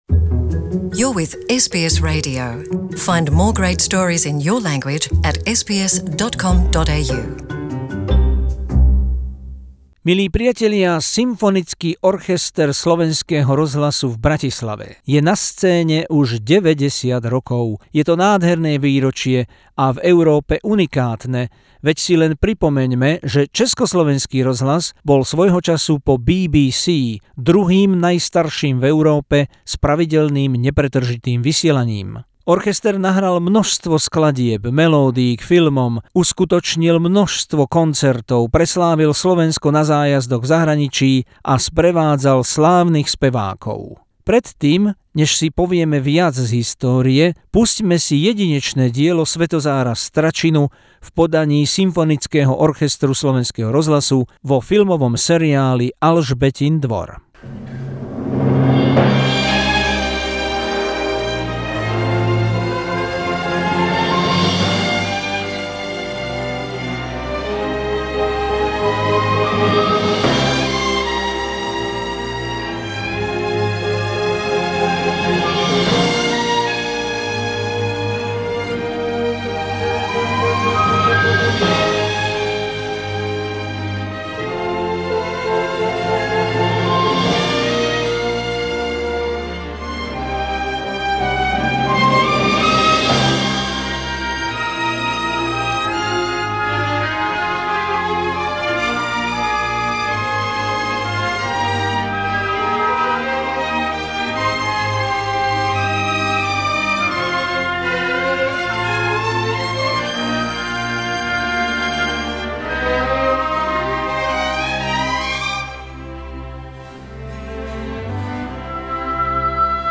Uchu lahodiace ukážky priložené.